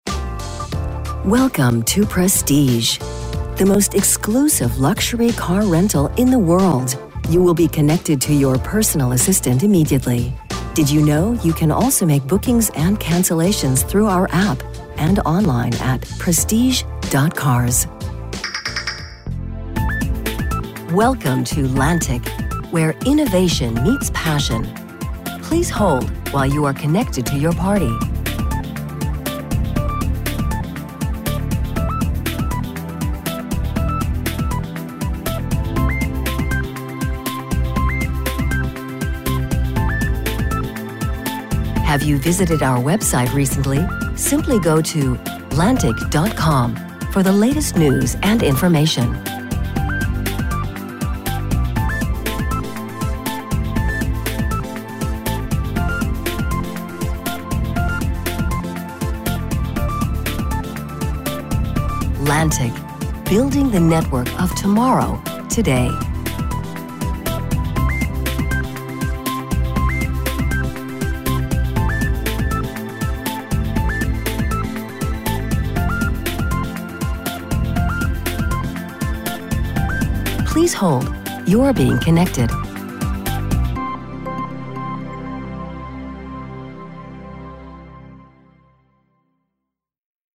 Native Speaker für Telefonansagen
Telefonansagen amerikanisch